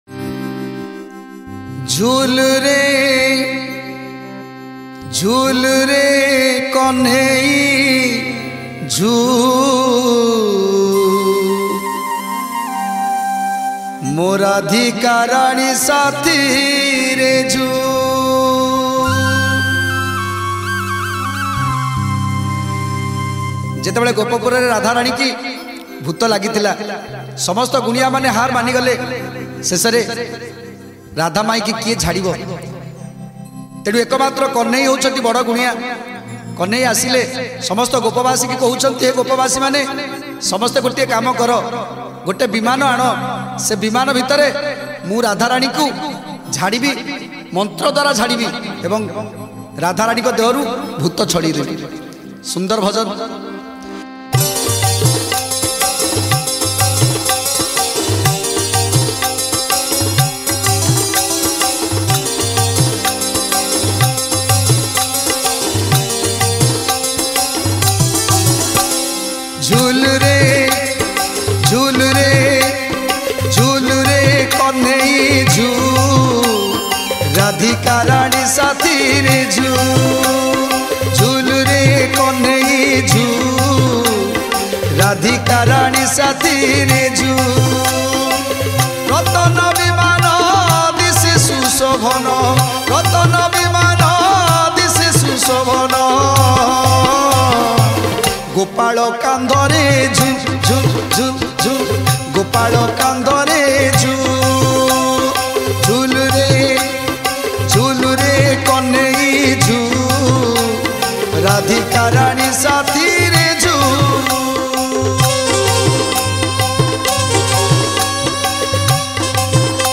Sri Sri Jagannath Stuti
Soulful Heart touching singer